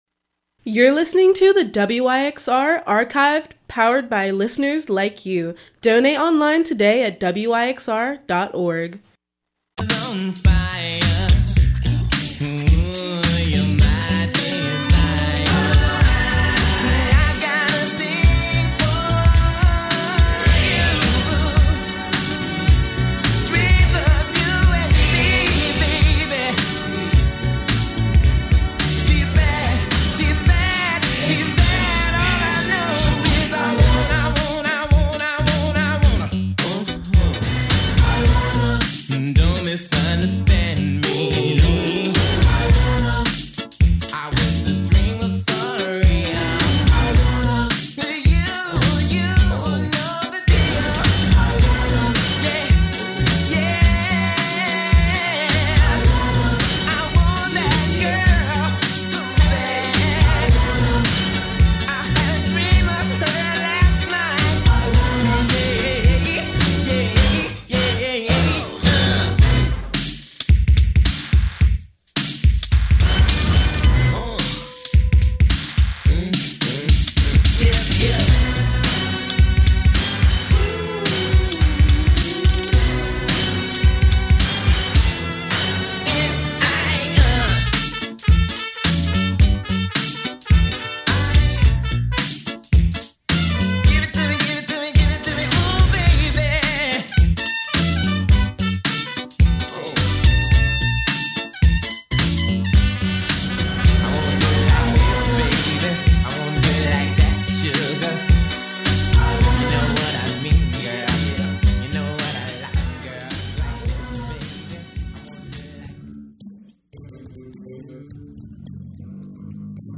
Neo Soul Funk Soul